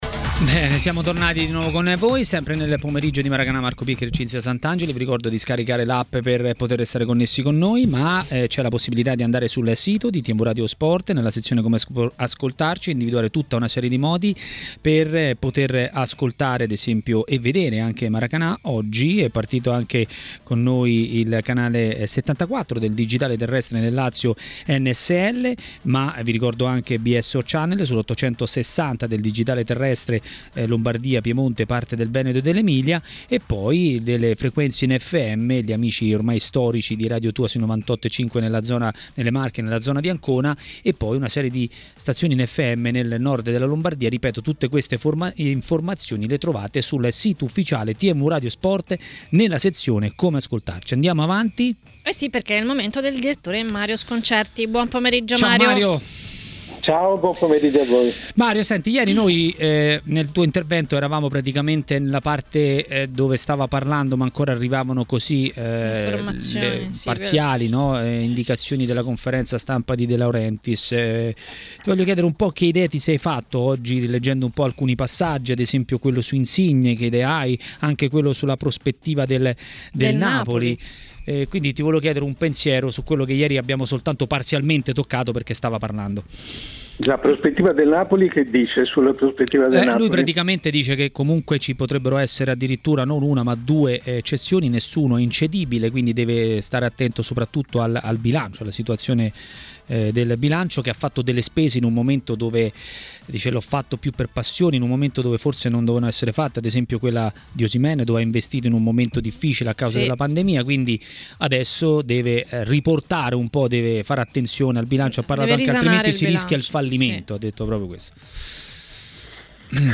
Il direttore Mario Sconcerti a TMW Radio, durante Maracanà, ha parlato dei temi del giorno.